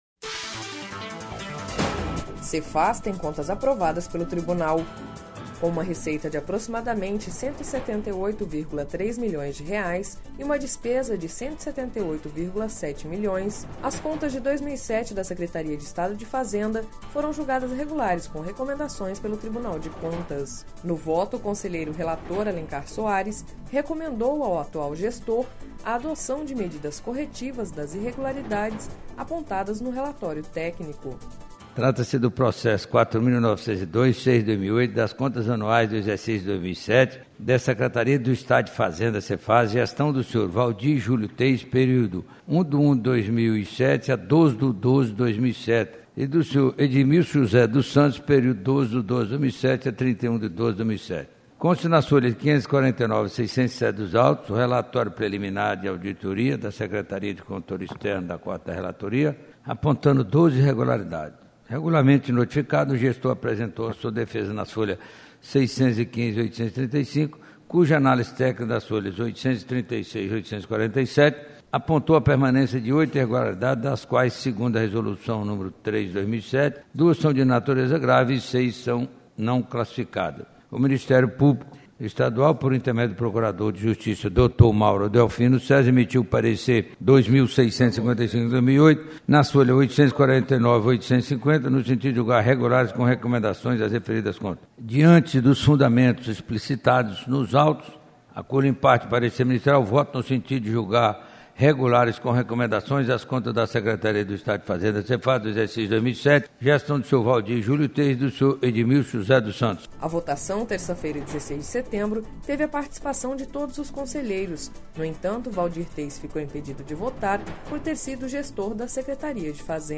Sonora: Alencar Soares - conselheiro do TCE-MT